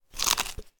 crunch.6.ogg